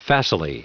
Prononciation du mot facilely en anglais (fichier audio)
Prononciation du mot : facilely